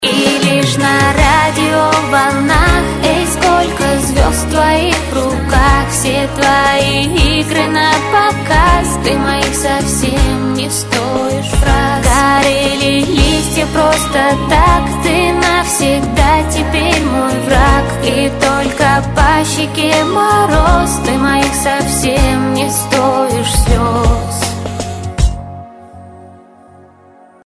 • Качество: 128, Stereo
спокойные